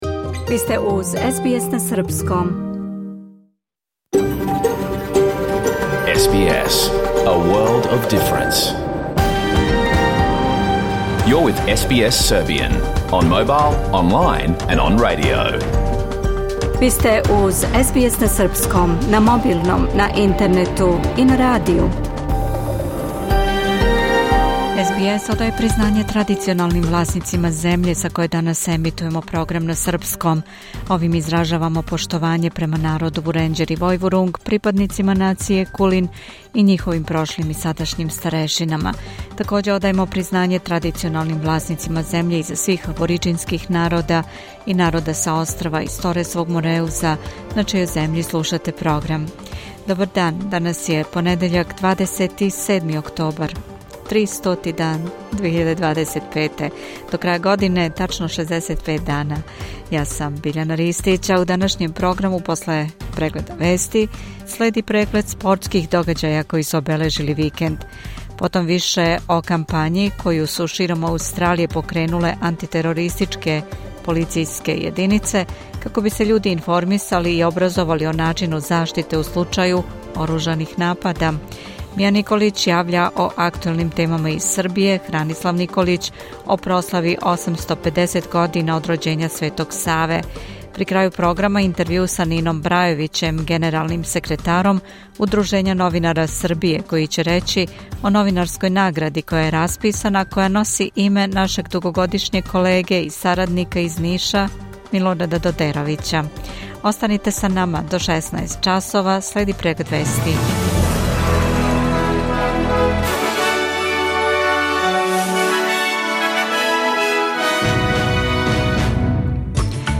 Програм емитован уживо 27. октобра 2025. године